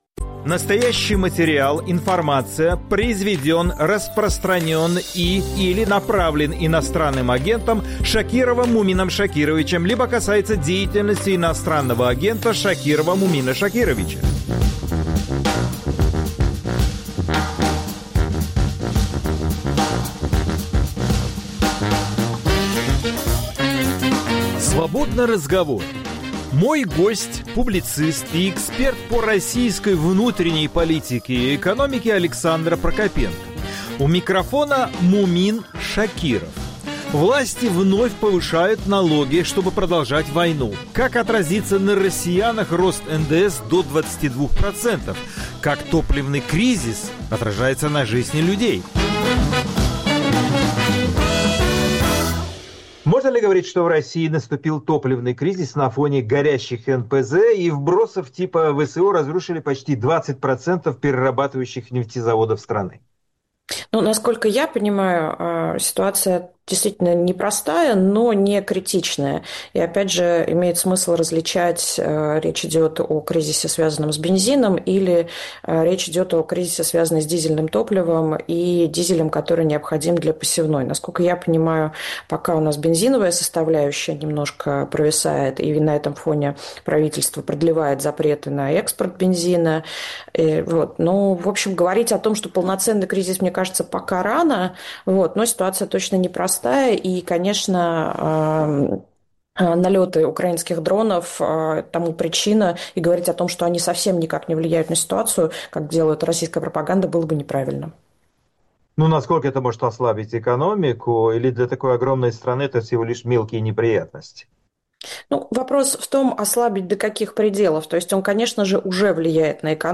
Свободный разговор